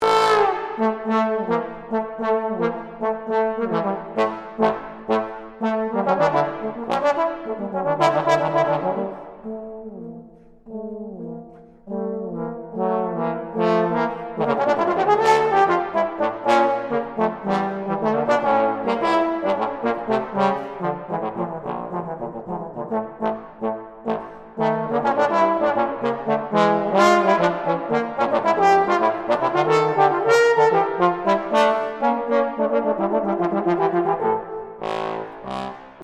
Trombone
Ik behoor tot het zwaar koper, maar mijn gewicht valt best wel mee, hoor!